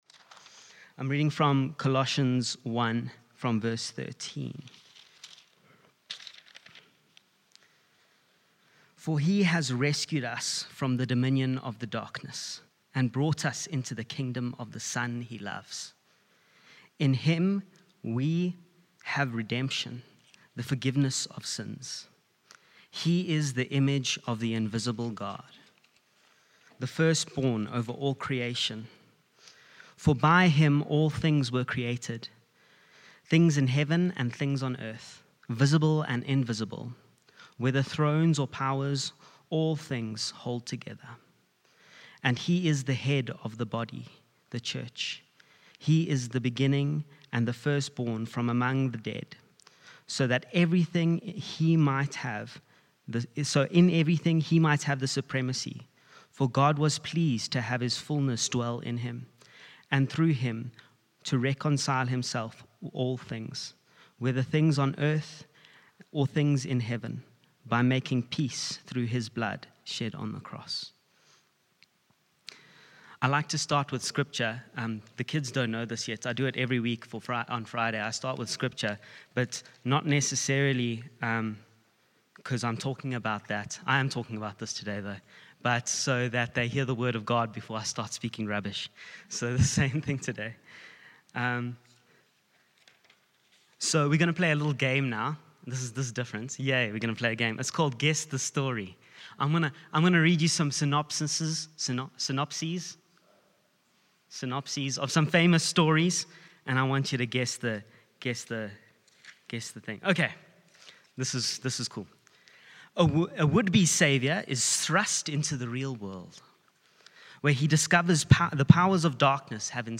From Hillside Vineyard Christian Fellowship, at Aan-Die-Berg Gemeente. Johannesburg, South Africa.